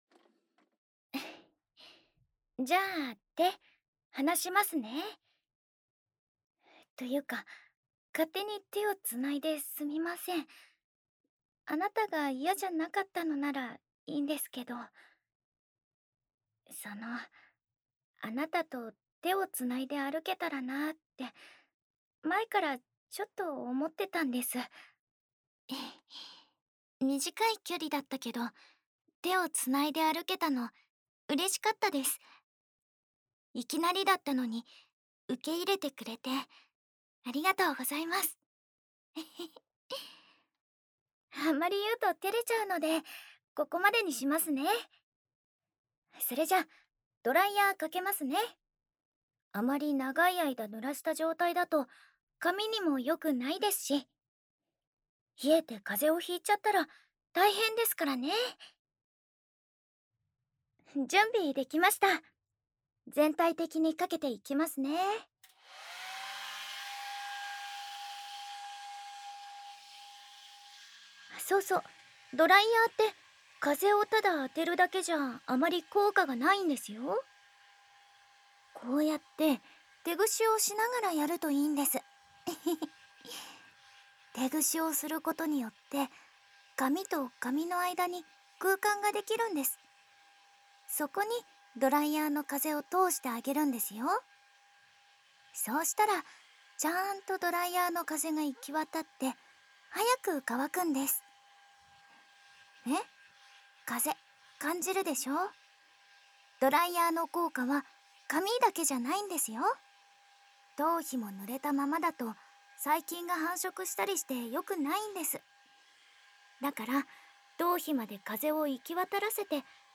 日常/生活 温馨 治愈 姐姐 环绕音 ASMR 低语
el62_06_『使用吹風機吹翻，在聽得到呼吸的距離下做頭皮按摩』.mp3